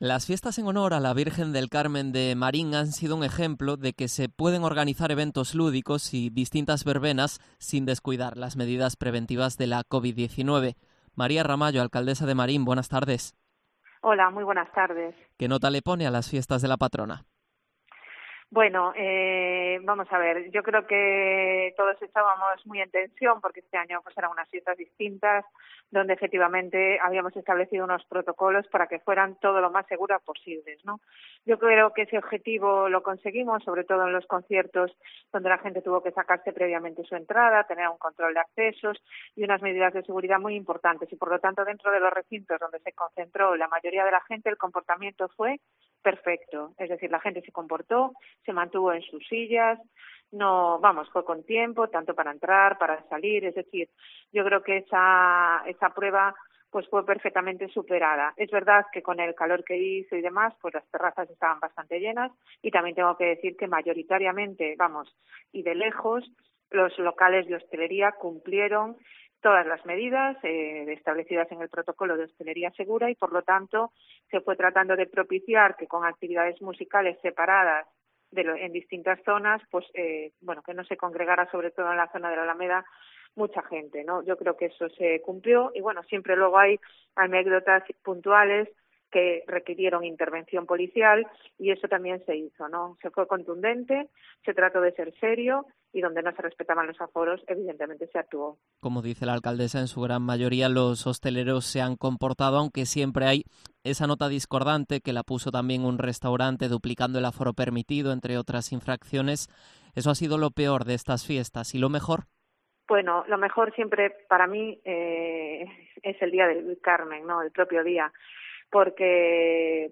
Entrevista a María Ramallo, alcaldesa de Marín